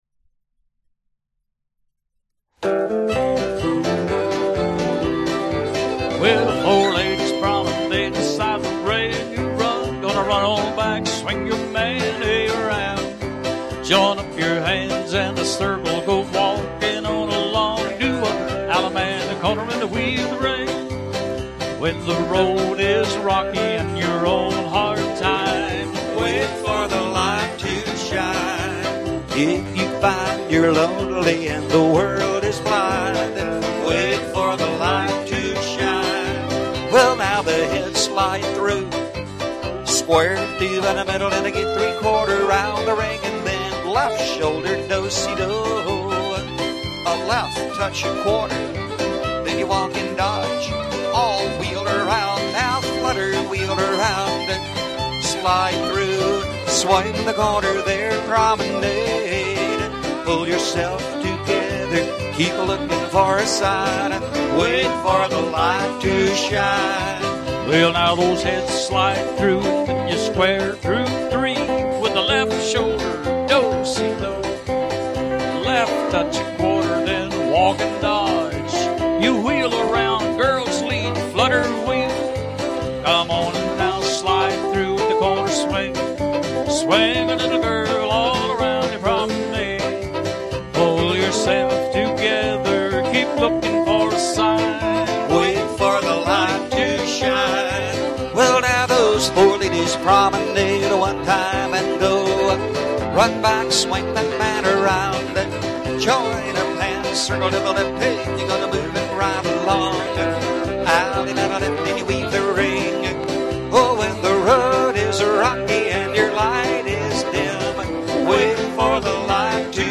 Singing Calls
Gospel